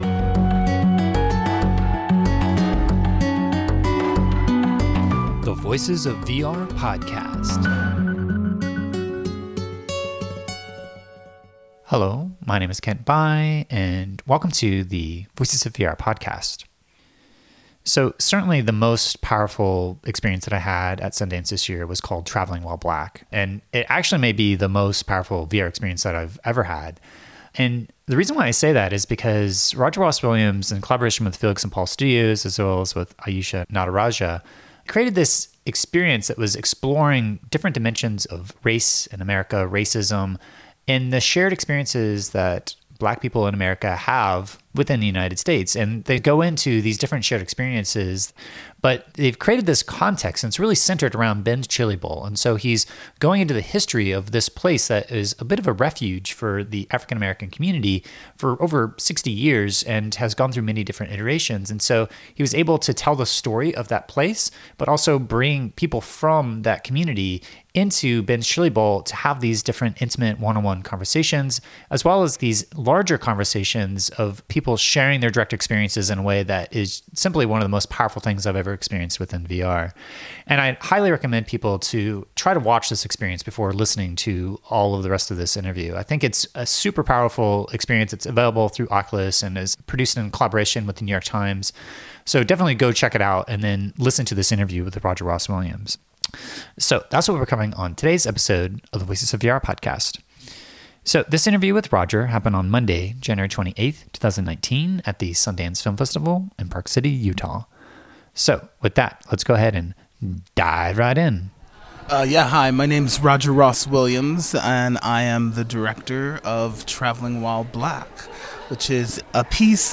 I had a chance to talk with Academy-award winning director Roger Ross Williams at Sundance where he shared his journey of creating Traveling While Black, how it received support from Tribeca, Sundance, Oculus the New York Times, and the MacArthur Foundation, the overwhelming emotional reactions that this piece was receiving at Sundance, and his own peak emotional experiences of discovering the power of the virtual reality medium throughout the production of this story.